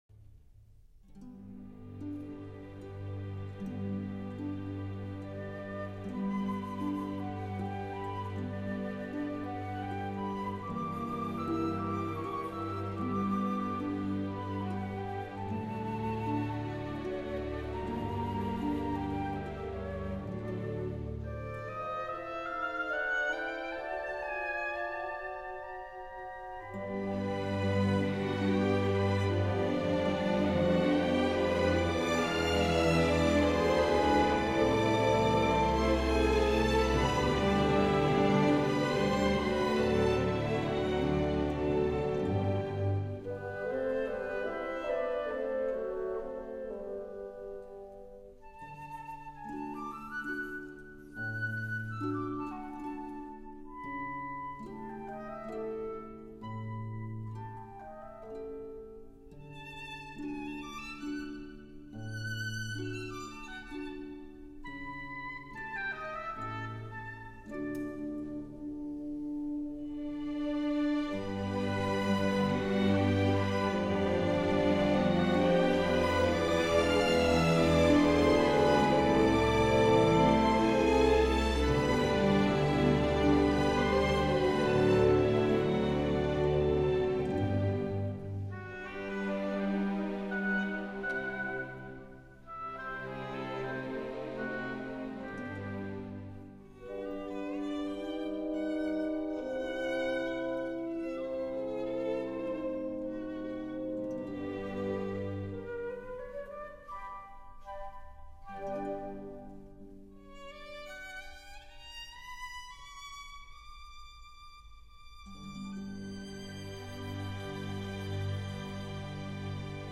Andante
行板 <02:03>